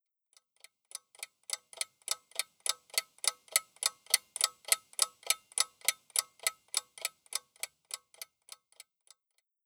tiktak.wav